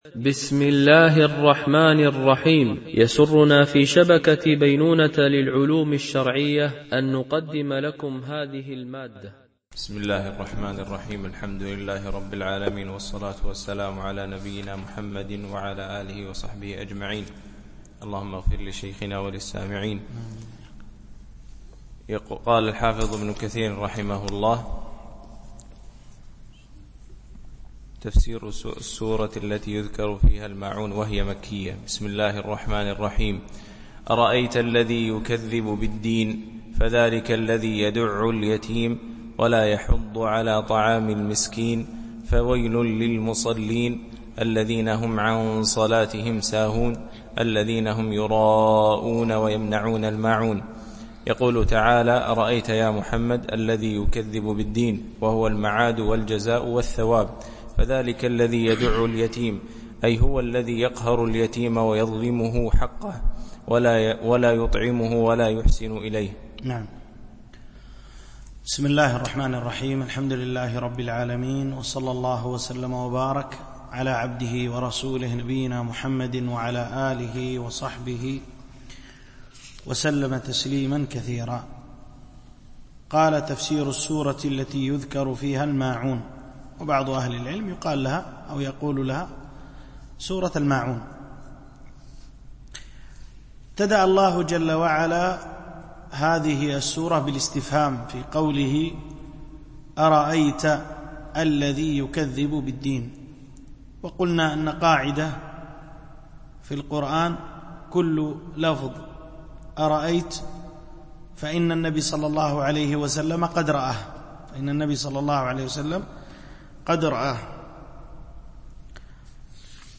شرح مختصر تفسير ابن كثير(عمدة التفسير) الدرس 61 (سورة الماعون الكوثر الكافرون النصر)
MP3 Mono 22kHz 32Kbps (CBR)